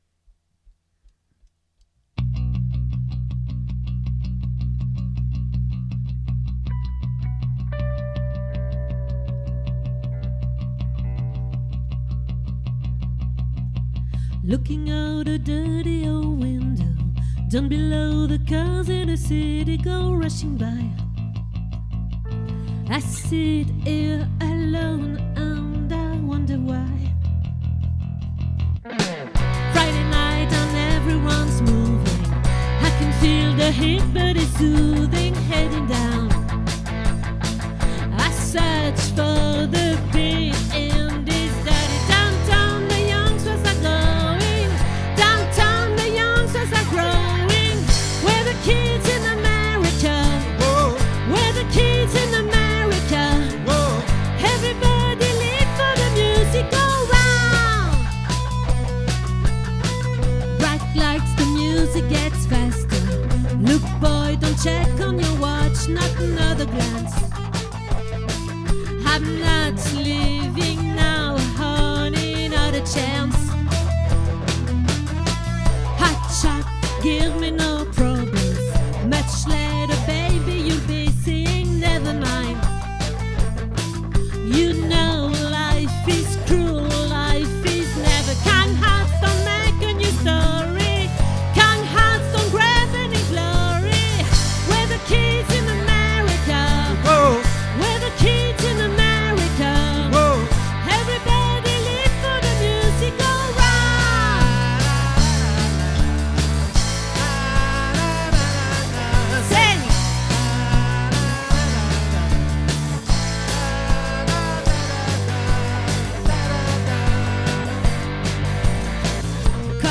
🏠 Accueil Repetitions Records_2025_09_15